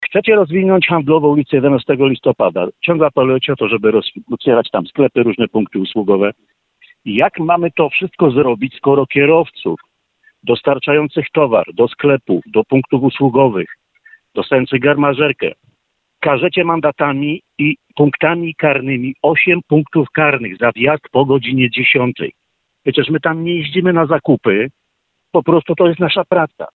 Temat poruszaliśmy w rozmowie z prezydentem Bielska-Białej.